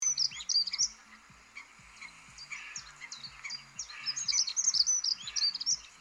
→ Chante un chant mélodieux et varié.
Le son de la Grive musicienne